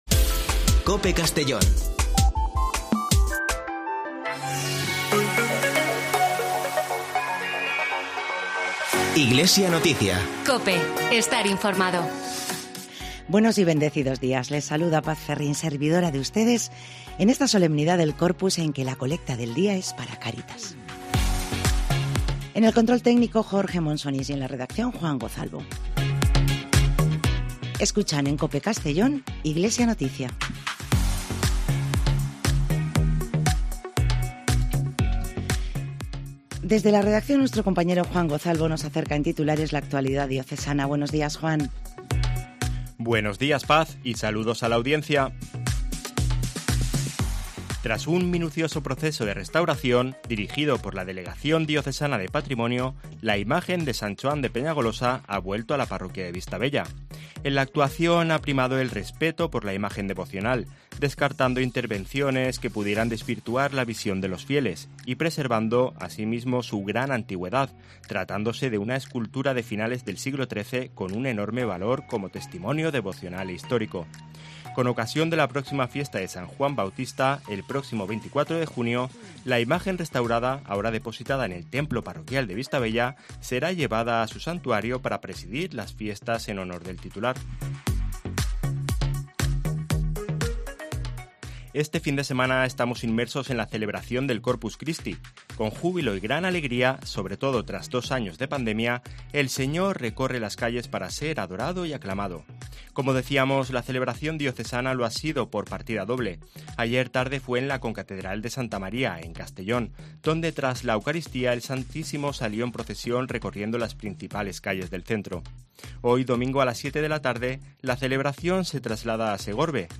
Espacio informativo de la Diócesis de Segorbe-Castellón